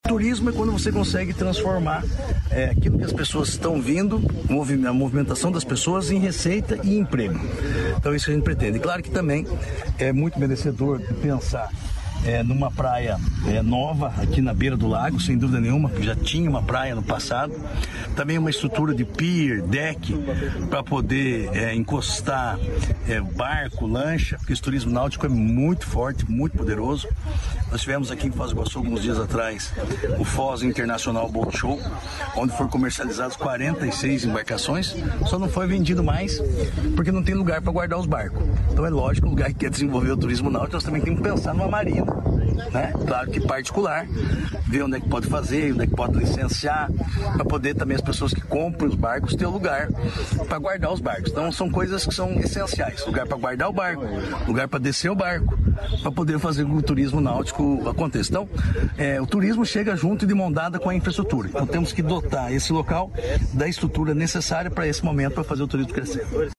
Sonora do secretário do Turismo, Márcio Nunes, sobre o crescimento do turismo no Oeste